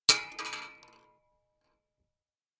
shotgun_metal_8.ogg